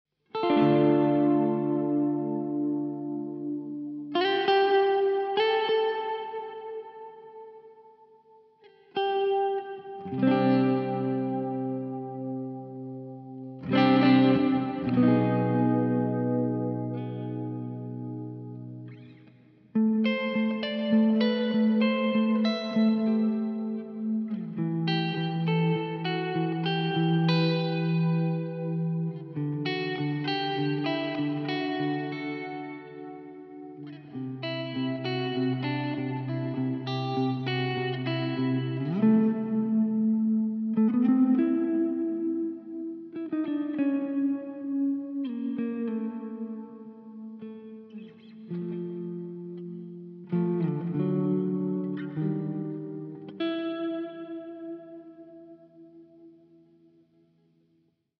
Aufgenommen wurden die folgenden Klangbeispiele an der Neck-Position einer Harley Benton CST-24. Der standardmäßig verbaute Tonabnehmer wurde durch einen Seymour Duncan SH-1 getauscht. Verstärkt wurde das Ganze mit einem Laney Ironheart Studio und einer emulierten Marshall 1960 Lead Box.
Das hier ist ein Dry/Wet-Regler, wie viel Hallsignal der trockenen Gitarre hinzugefügt werden soll.
9 Uhr
tb_audio_touchmyhall_05_hall_blend_09_uhr.mp3